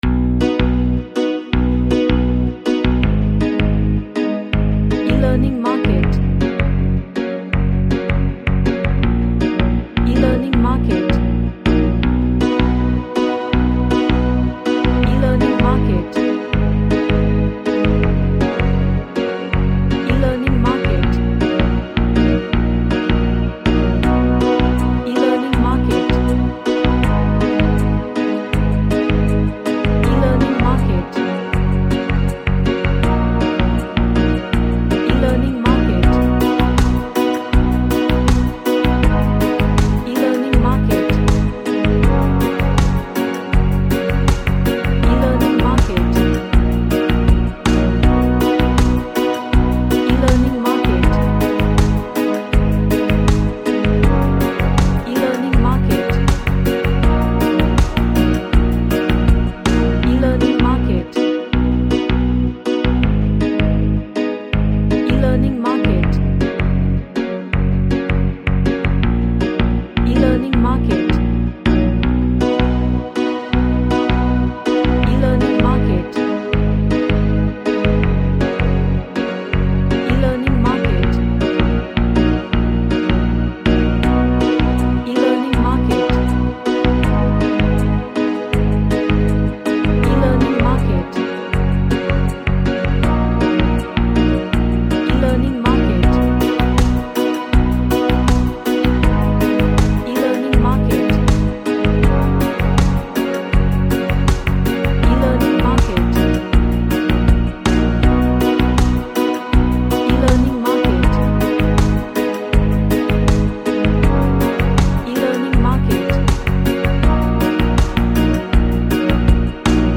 A reggae track with guitar ambience and melody.
Upbeat